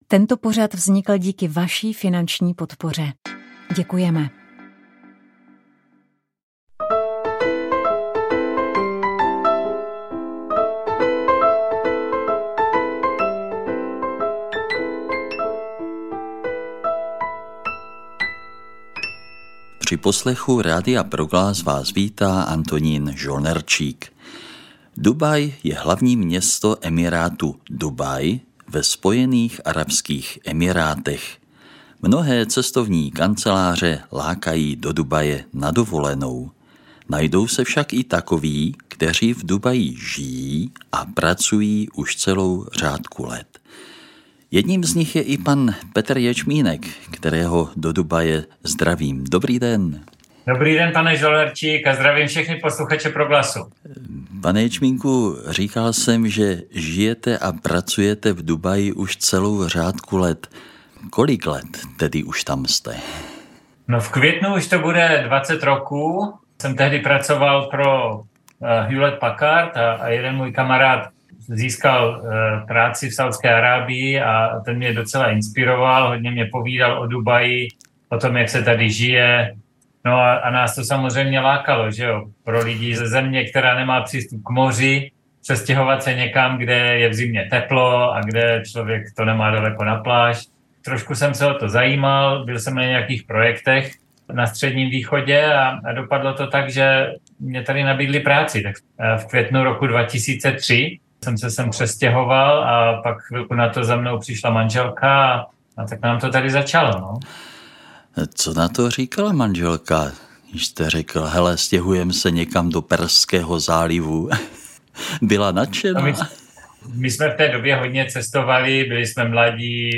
V pořadu z cyklu Na stole je téma navštívíme areál zámku v Doudlebách nad Orlicí. Nepůjde však o klasickou prohlídku zámku, ale zavítáme do nového stálého trampského muzea česko-slovenského trampingu.